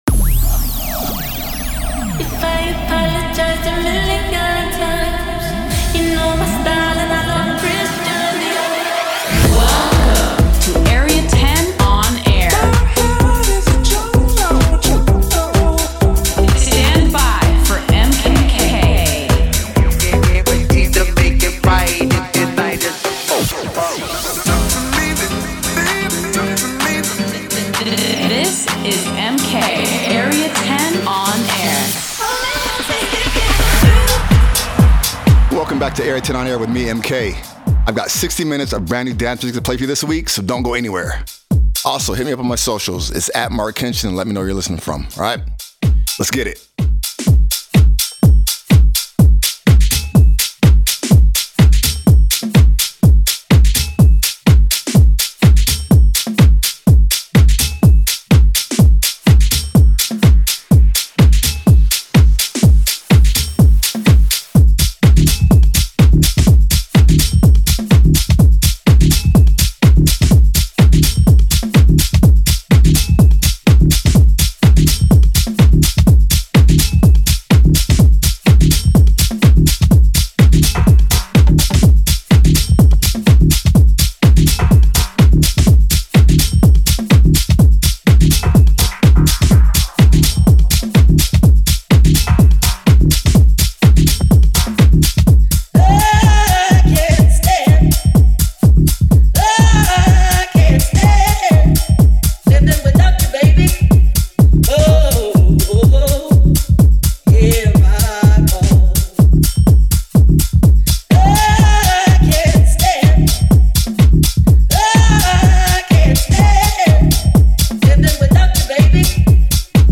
Delving in to house and techno